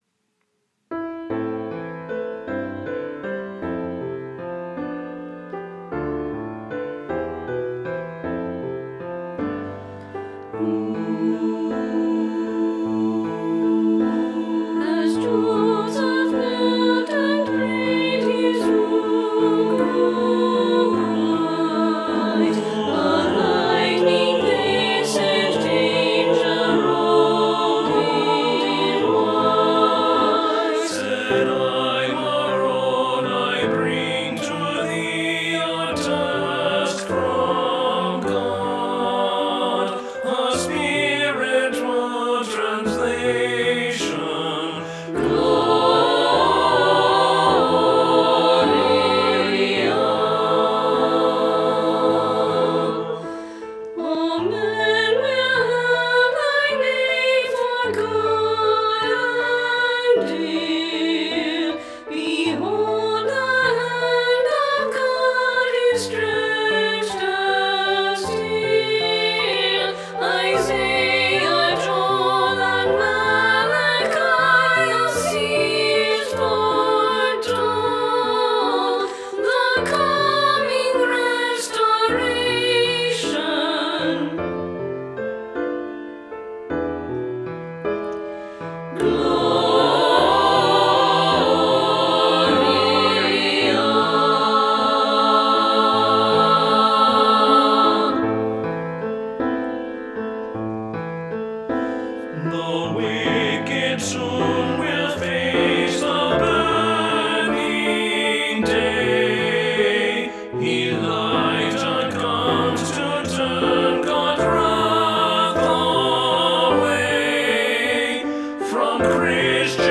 Voicing/Instrumentation: SATB